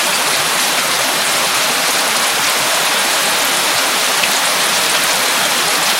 Heavy Rain
Intense heavy rainfall on mixed surfaces with steady downpour and splashing puddles
heavy-rain.mp3